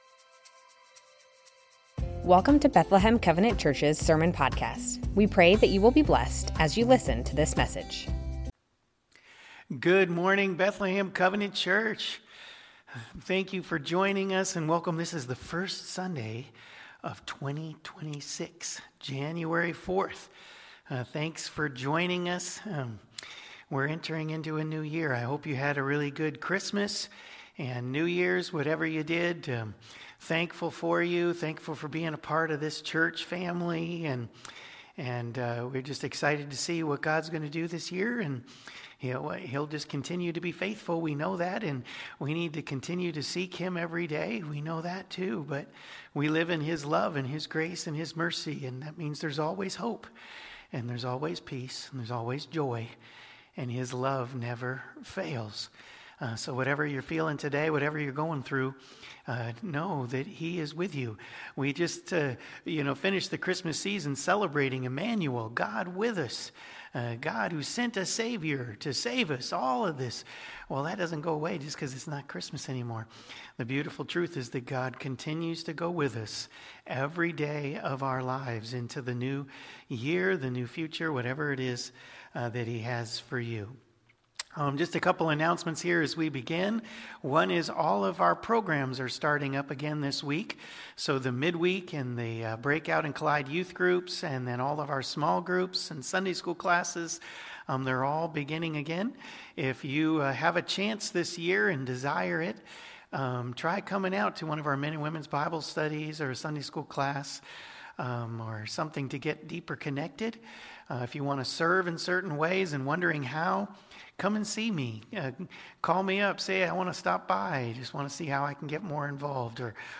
Bethlehem Covenant Church Sermons The Ten Commandments Jan 04 2026 | 00:32:58 1x 00:00 / 00:32:58 Subscribe Share Spotify RSS Feed Share Link Embed